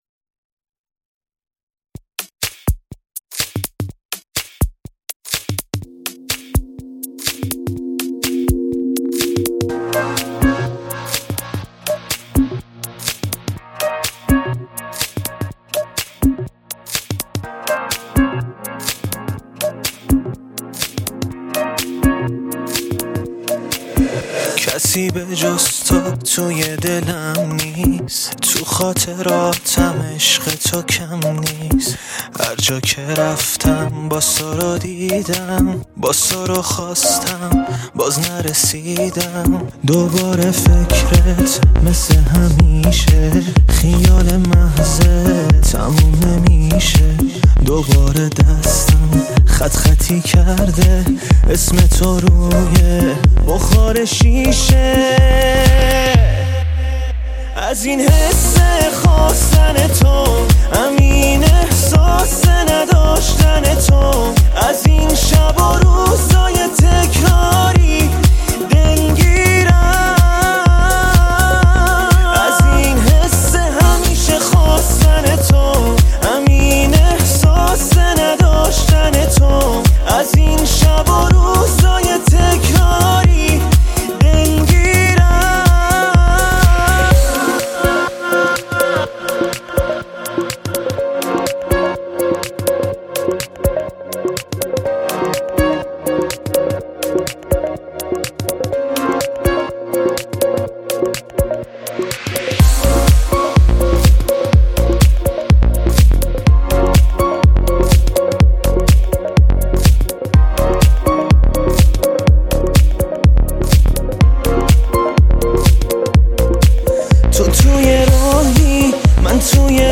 غمگین
پاپ